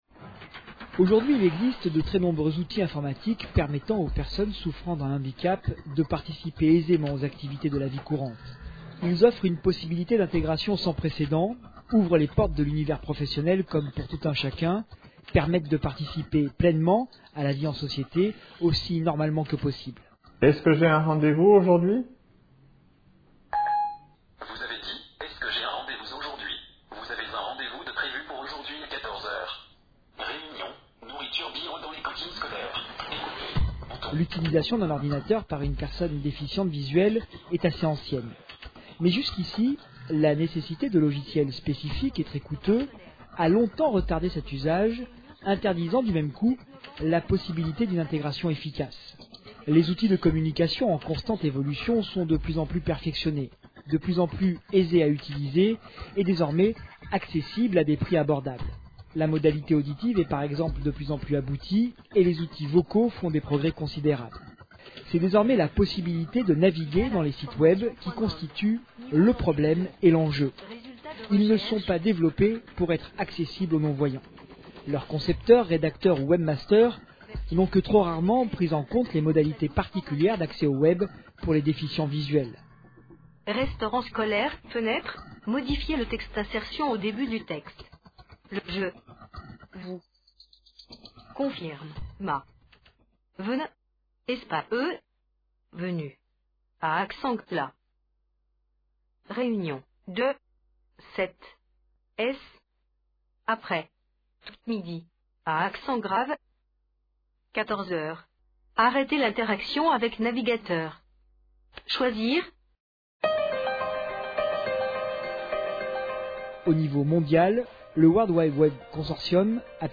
1. L'ordinateur des aveugles - la synthèse vocale Certains smartphone, certaines tablettes tactiles intègrent des fonctionnalités d'accès pour les personnes en situation de handicap.